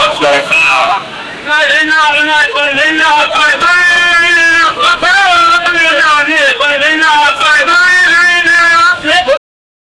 the-noise-of-the-nigerian-feqftsuw.wav